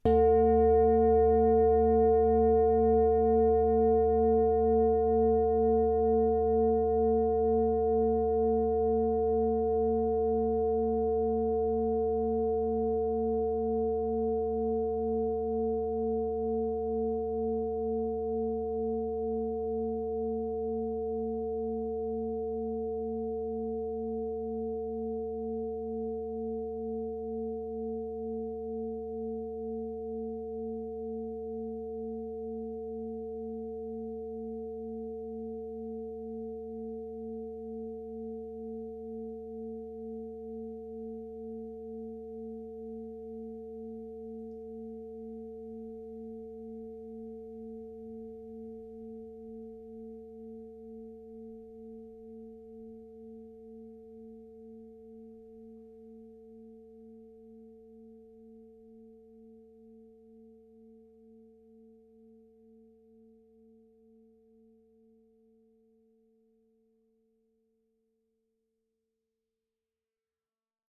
Der nachfolgende Link sollte Ihnen die Aufnahme eines einzelnen Schlages auf eine Klangschale in einem neuen „Tab“ vorspielen.
Den meisten Menschen wird es wahrscheinlich ein Leichtes sein, zumindest zwei, wenn nicht drei oder gar vier verschiedene Töne auszumachen, die gleichzeitig zu hören sind.
Links der kleine schwarze Rand steht für eine kurze Stille bis der Schlag erfolgt, dessen Klang dann nach rechts hin leiser wird.
einzelner_klangschalenschlag.ogg